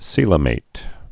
(sēlə-māt)